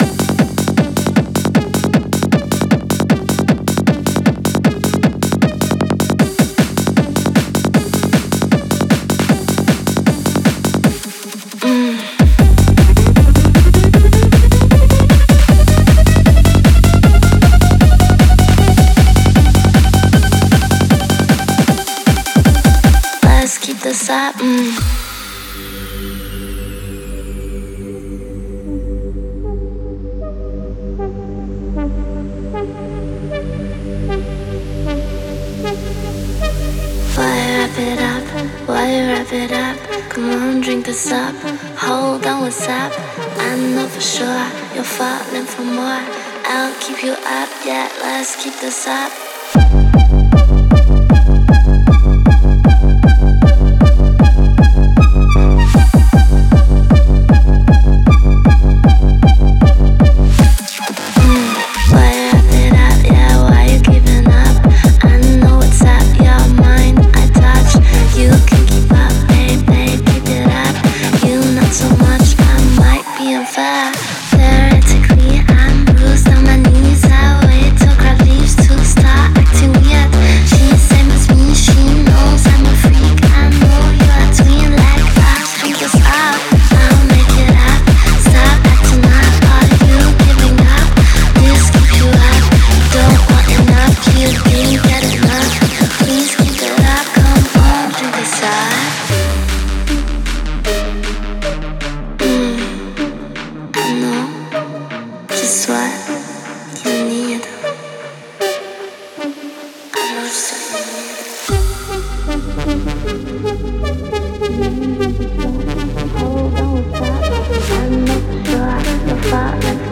Après Mastering